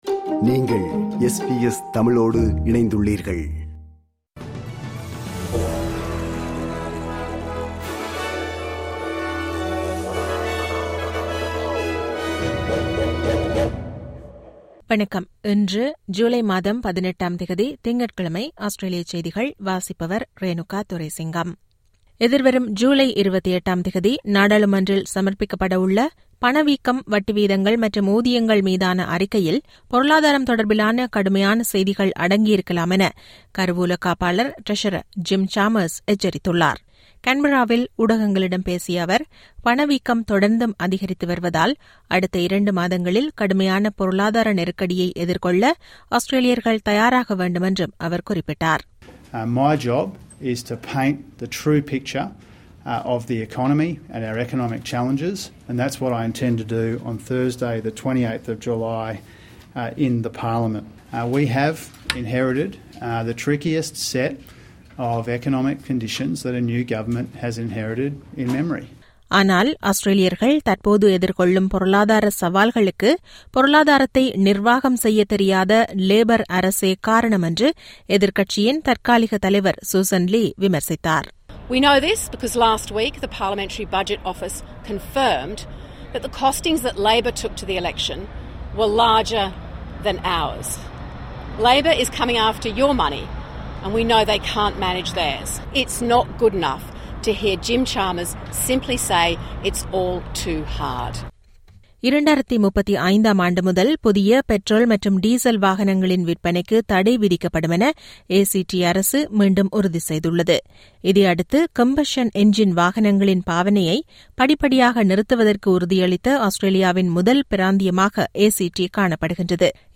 Australian news bulletin for Monday 18 July 2022.